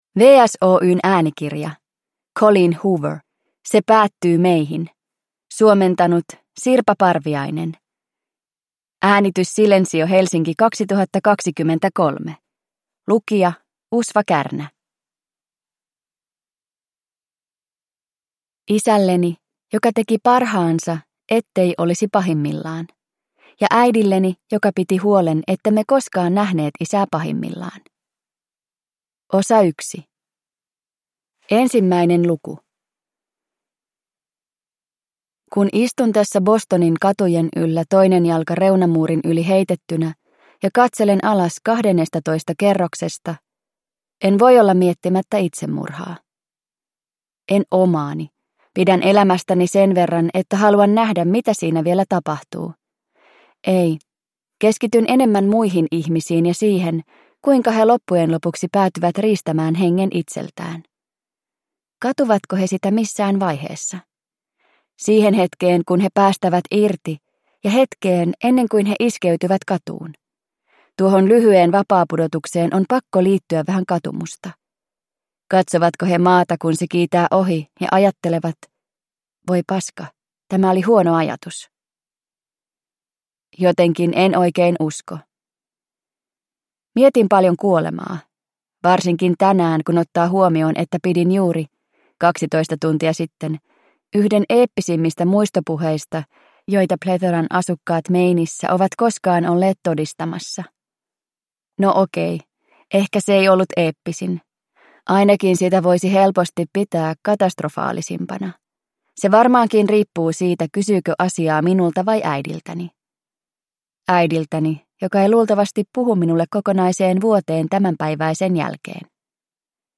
Se päättyy meihin – Ljudbok – Laddas ner